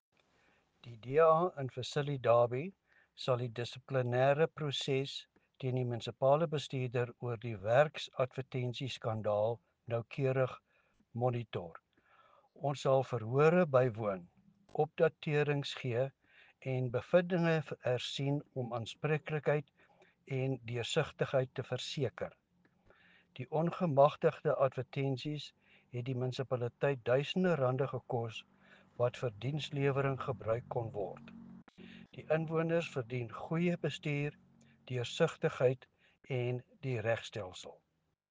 Afrikaans soundbite by Cllr Louis van Heerden.